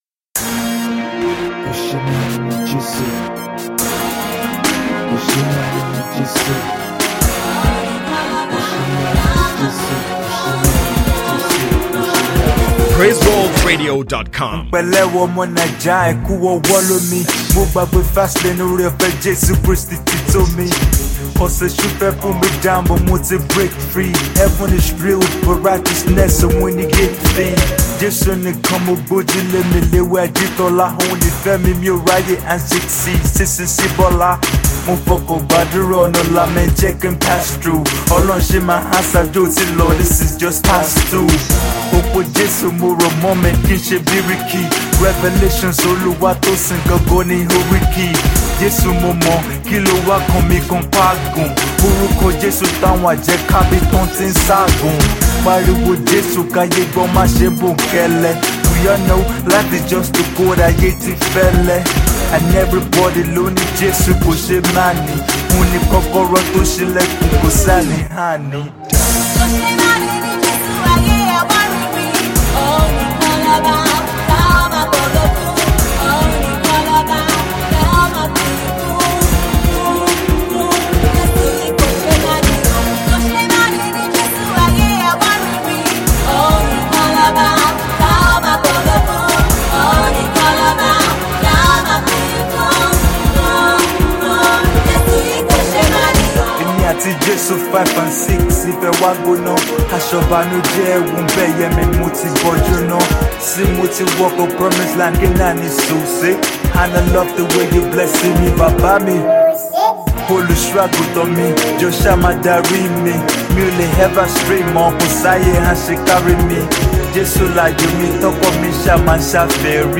gospel Emcee
water tight indigenous lyrical deliveries!